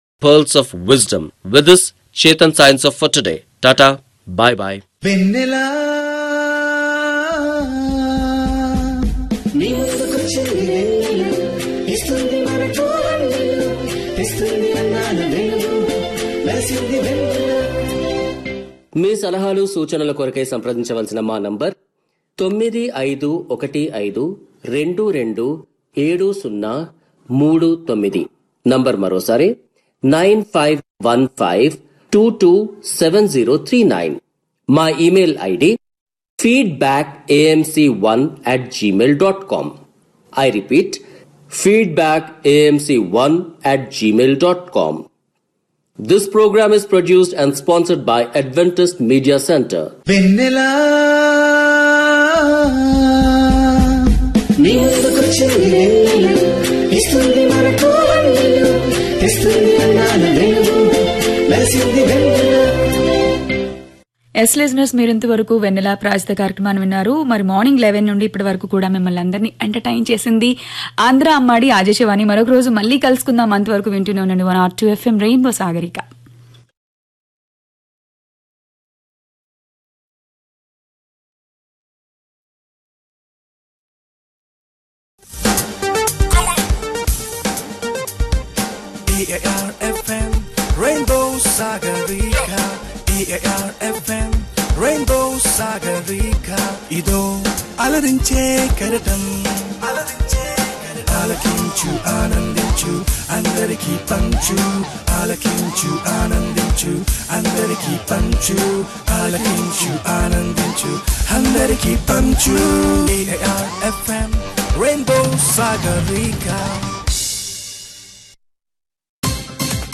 Programs of Adventist Media Centre, Pune, India broadcast via All India Radio